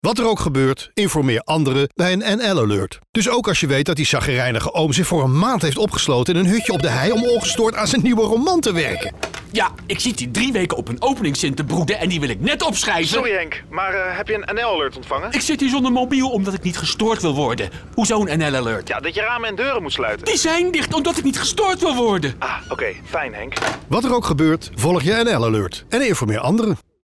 Er zijn twee radiospots voor de campagne informeer anderen:
NL-Alert informeer anderen | Radiospot Hutje